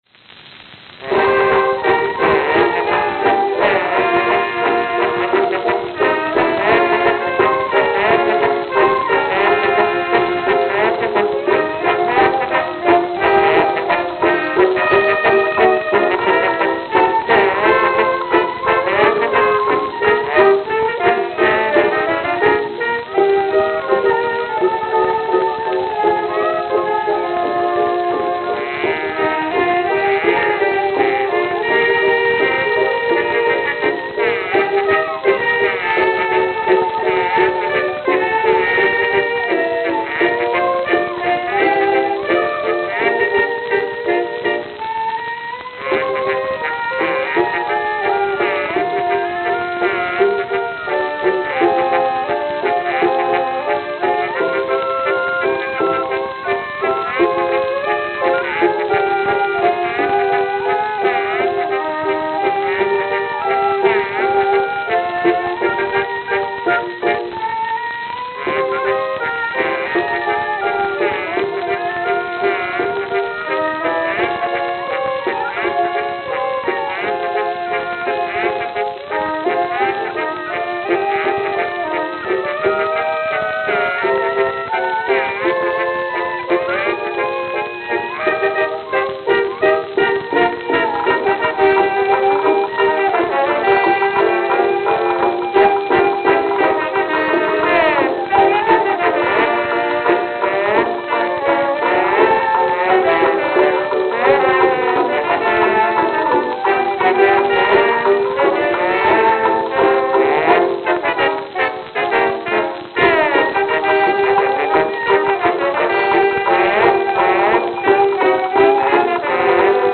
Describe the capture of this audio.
Note: Poor shellac.